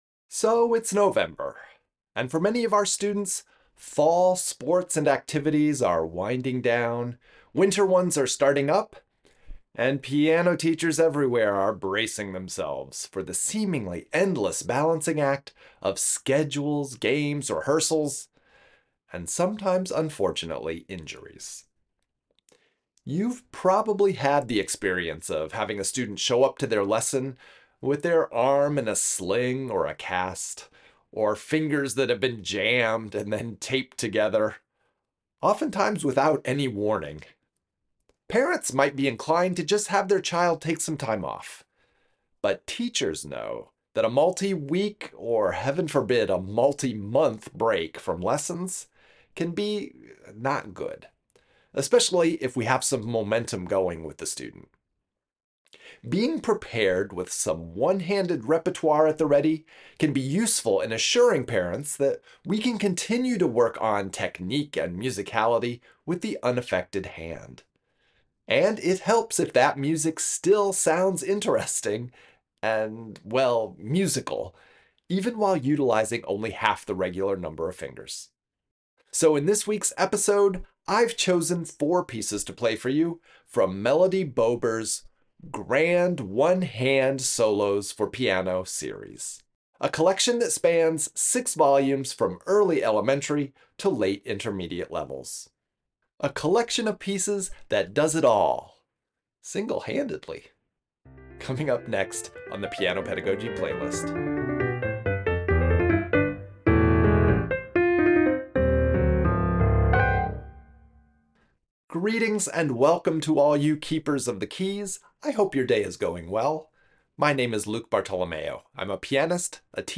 Lazy Day, for left hand alone – Digital Download (from book 2, Digital Download Printed Copy)